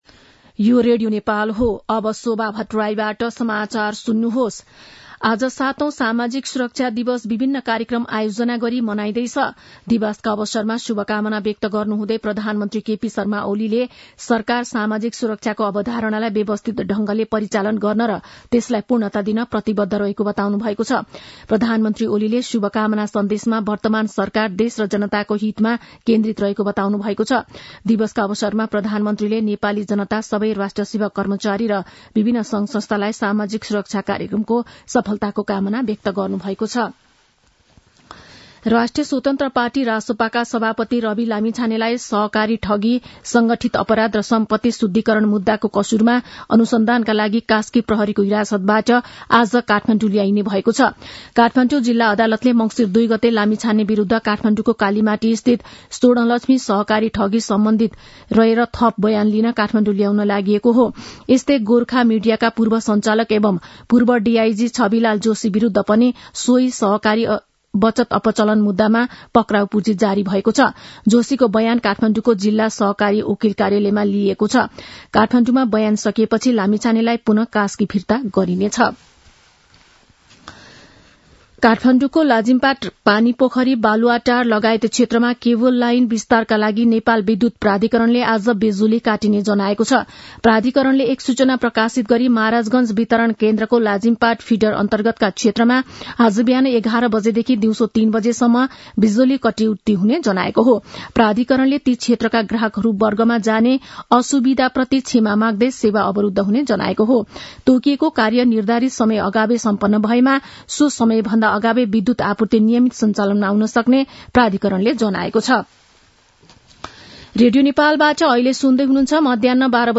मध्यान्ह १२ बजेको नेपाली समाचार : १२ मंसिर , २०८१
12-am-nepali-news-1-8.mp3